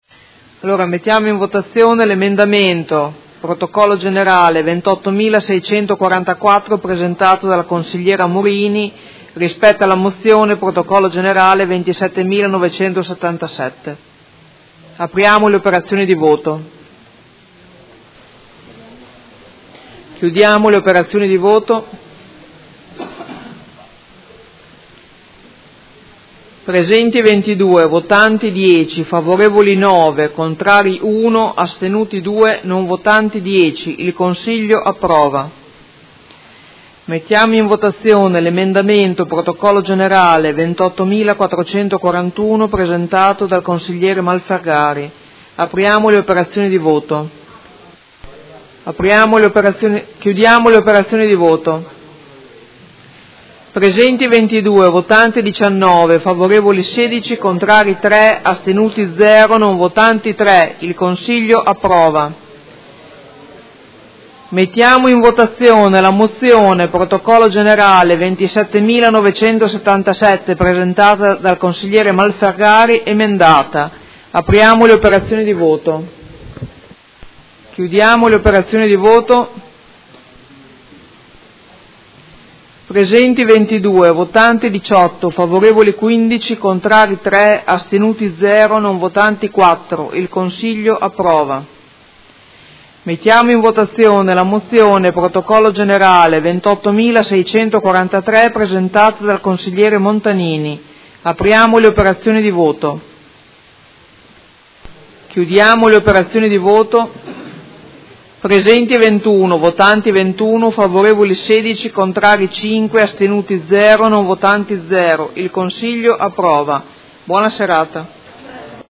Seduta del 24/01/2019. Mette ai voti emendamenti e mozioni.